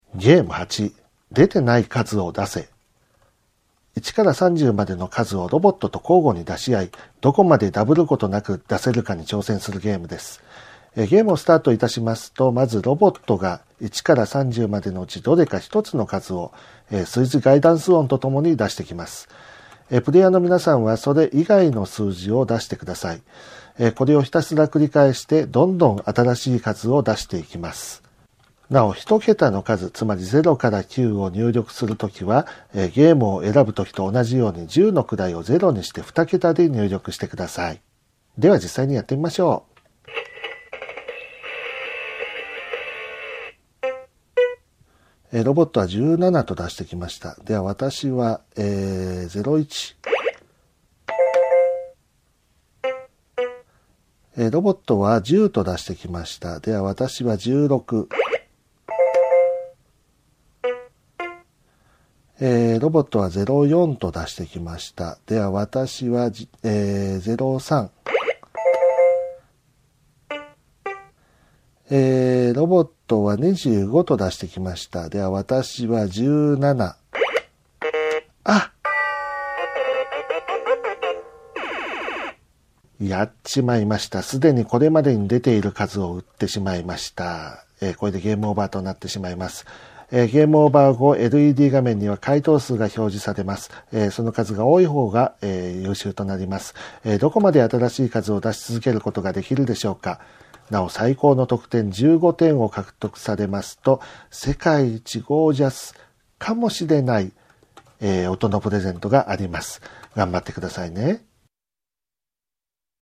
ゲームロボット５０の遊び方音声ガイド
遊び方の説明書は商品に同梱していますが、目の不自由な方にも遊んでいただけるよう音声による遊び方の説明をご用意しております。